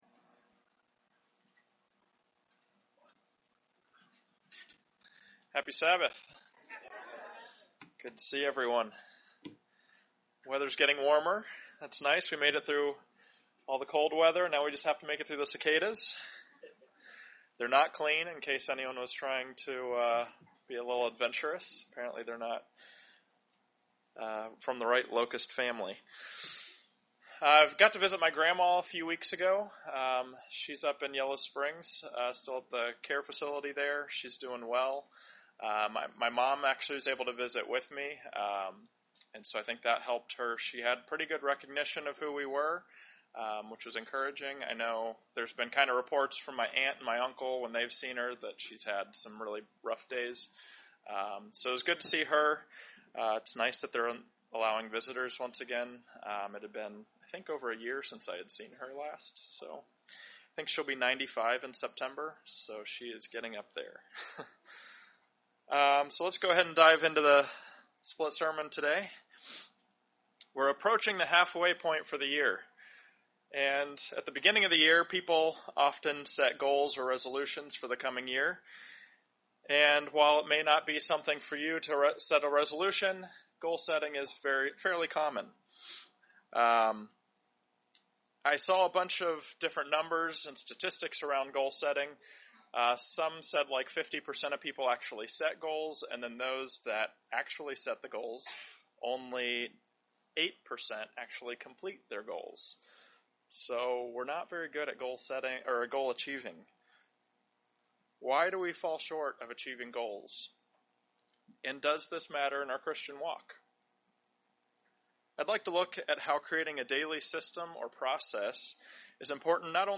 Sermons
Given in Dayton, OH